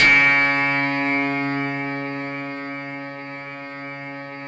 RESMET C#3-L.wav